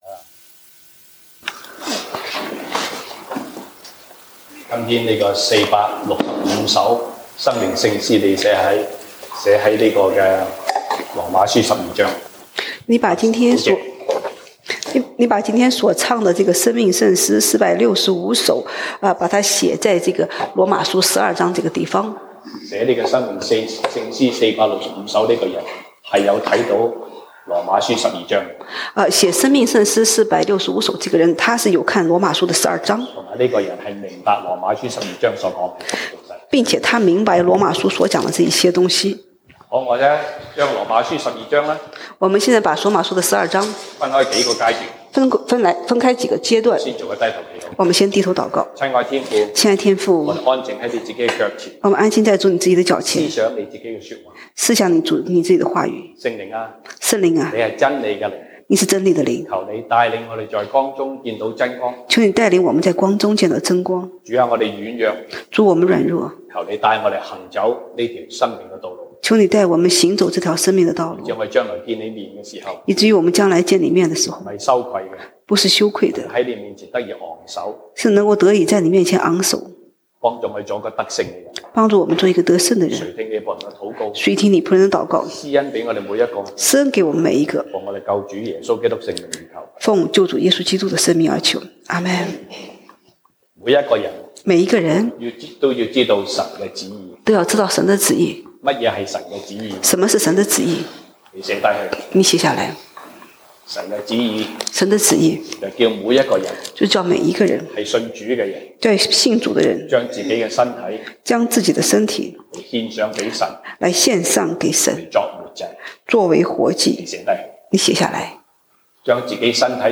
西堂證道(粵語/國語) Sunday Service Chinese: 靠聖靈行事